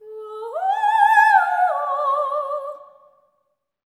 OPERATIC01-R.wav